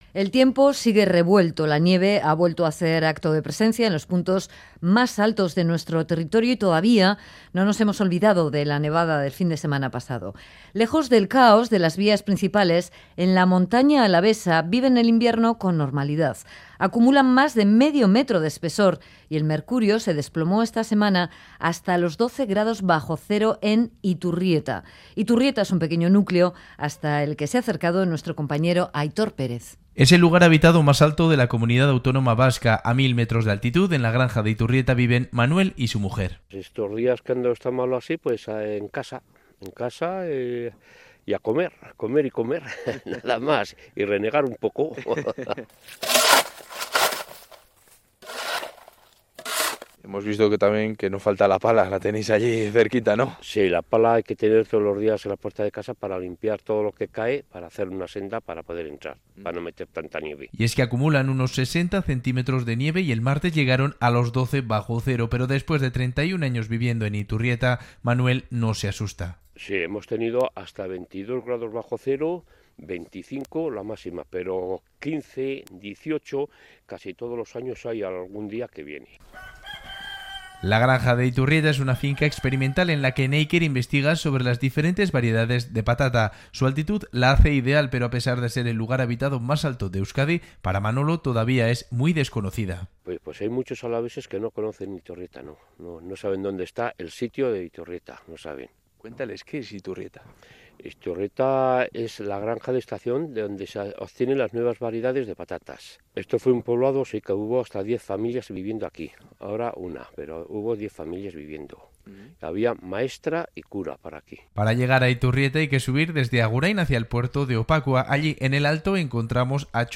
Radio Euskadi REPORTAJES